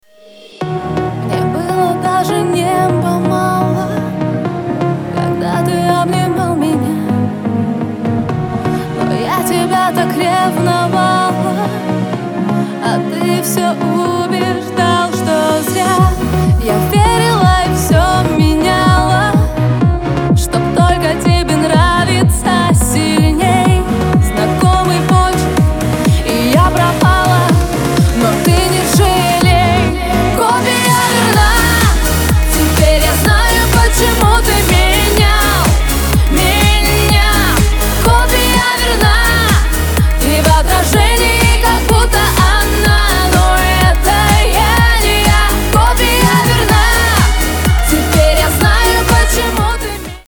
• Качество: 320, Stereo
поп
dance
vocal